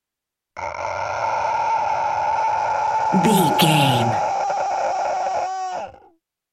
Monster pain growl little creature
Sound Effects
scary
dark
eerie